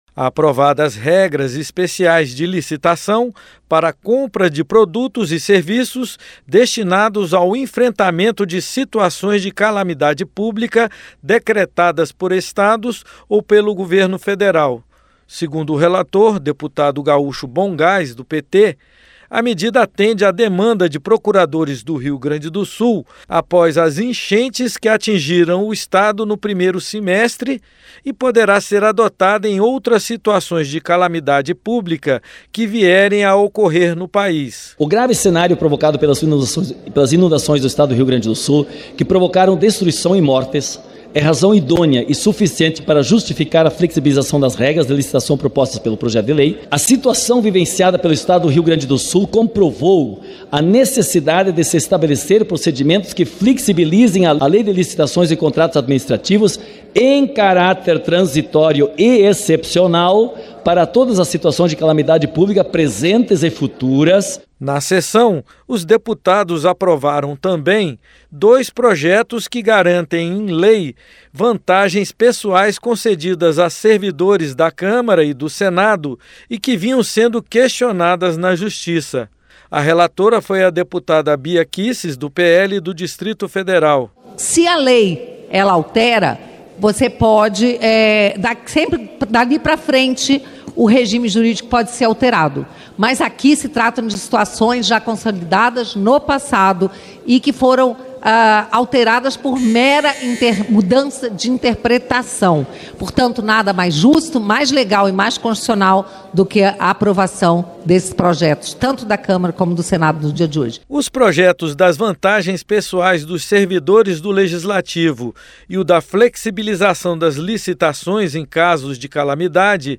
PLENÁRIO DA CÂMARA APROVA MUDANÇAS NAS NORMAS PARA AS LICITAÇÕES DIANTE DE SITUAÇÕES DE EMERGÊNCIA COMO A DAS INUNDAÇÕES QUE CASTIGARAM O RIO GRANDE DO SUL. O REPÓRTER